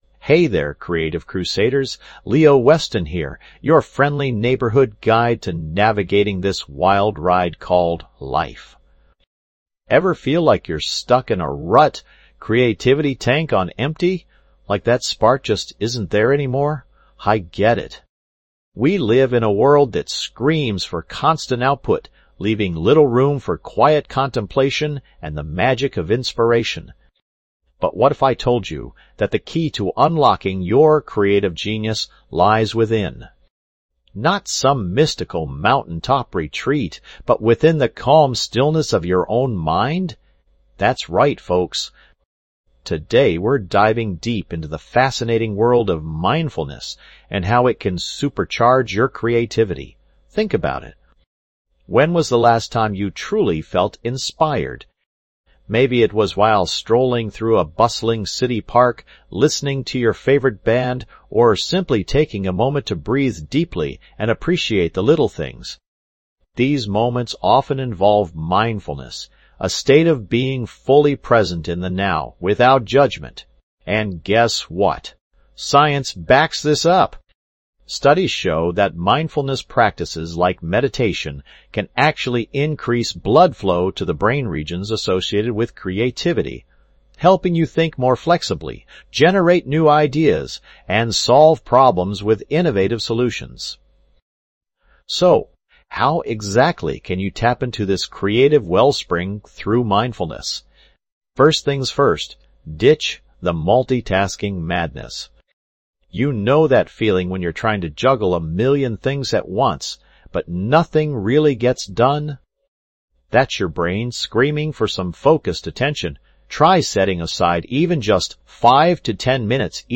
Podcast Category:. Self-improvement, Personal Development, Mindfulness Meditation
This podcast is created with the help of advanced AI to deliver thoughtful affirmations and positive messages just for you.